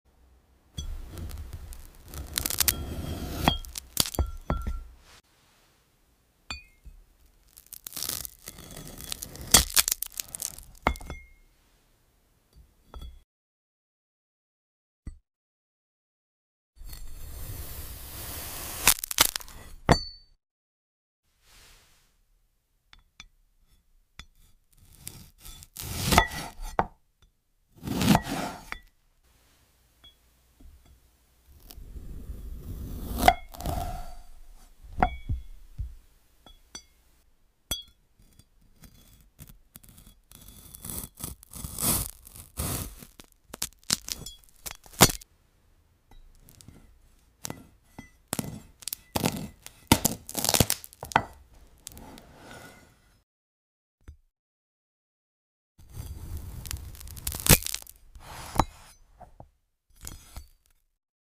cutting glass oranges in this sound effects free download
cutting glass oranges in this calming asmr video